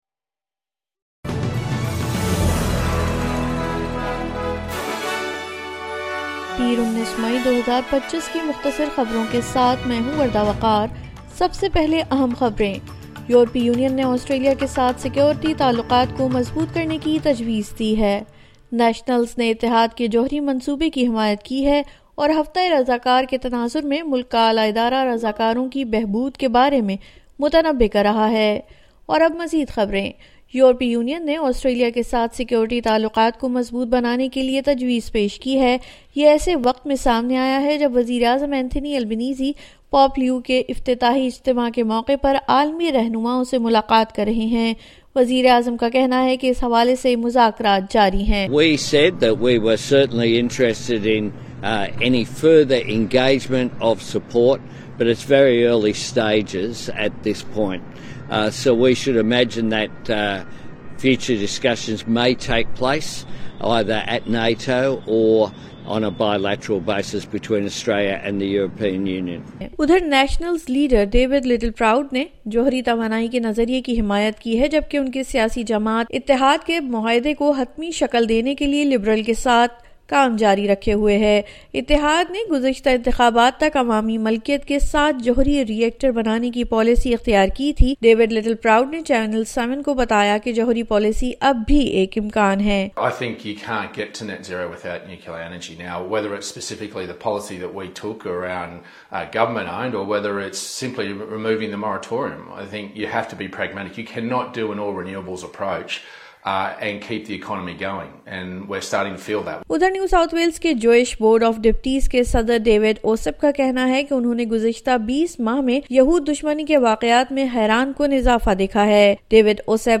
مختصر خبریں: پیر 19 مئی 2025